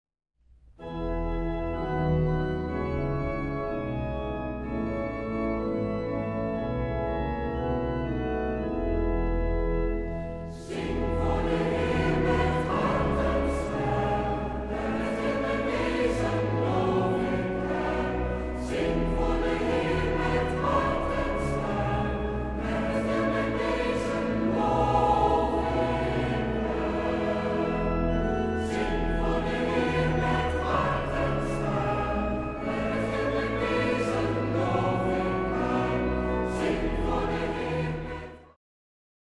orgel
piano
fluit
hobo
trompet
slagwerk